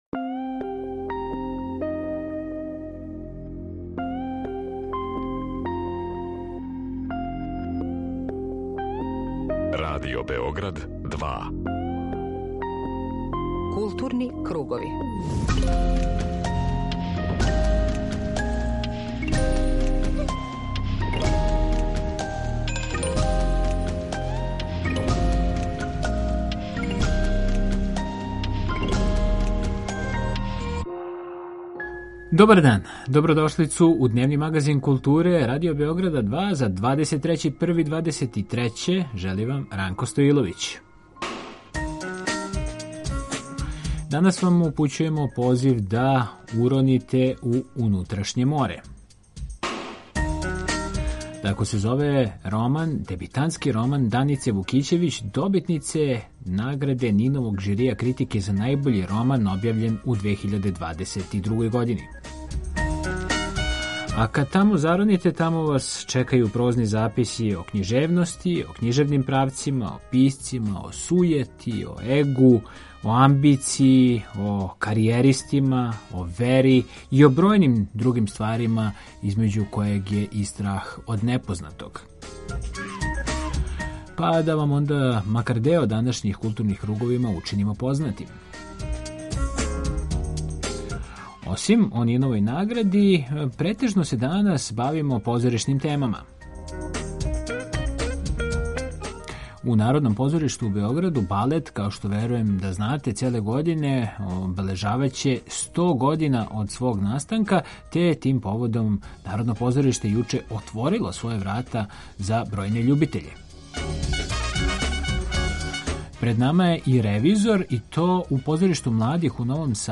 Како би што успешније повезивали информативну и аналитичку компоненту говора о култури у јединствену целину и редовно пратили ритам културних збивања, Кругови имају магазински карактер.
Међу њима је и овогодишњи добитник, а ми у Круговима одмах доносимо извештај са проглашења.